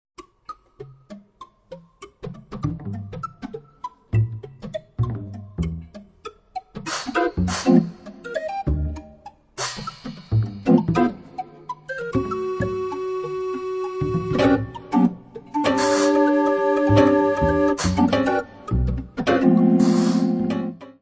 barrel organ
piano, prepared piano
tuba, serpent
double bass
percussion
Music Category/Genre:  New Musical Tendencies